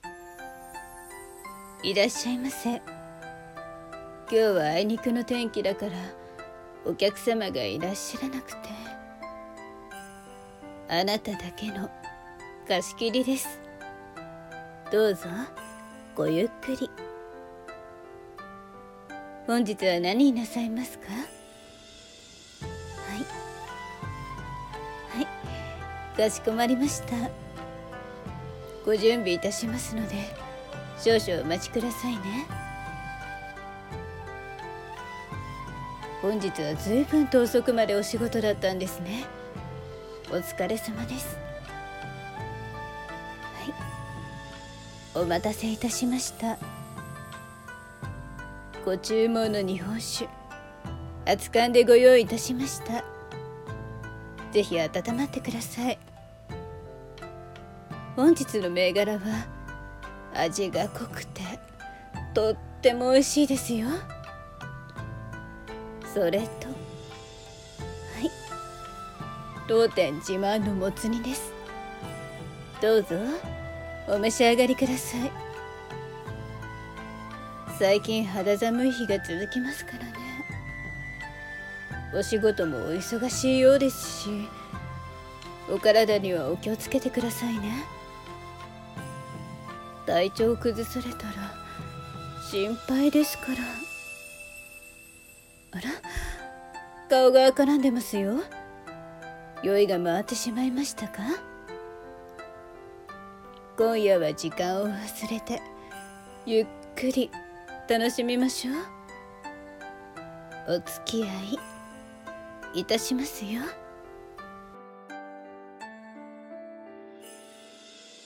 小料理屋の女将ボイス